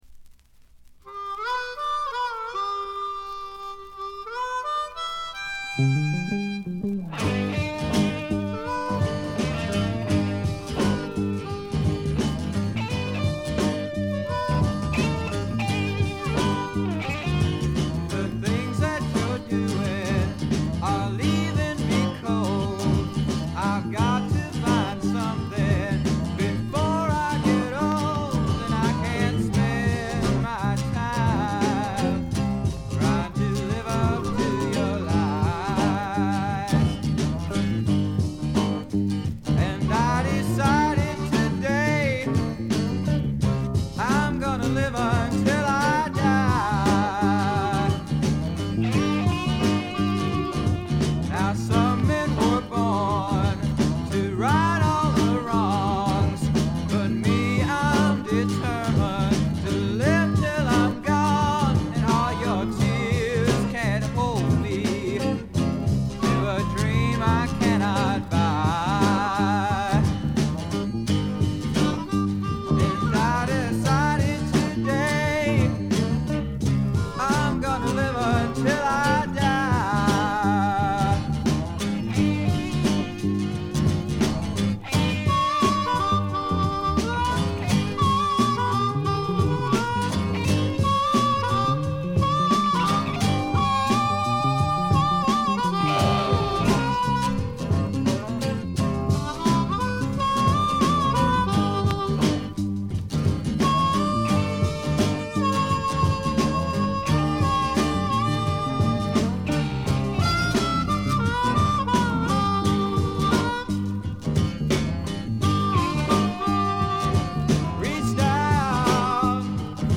静音部での軽微なバックグラウンドノイズ程度。
素晴らしいサイケデリック名盤です。
試聴曲は現品からの取り込み音源です。
Recorded At - Sound City Inc, Recording Studios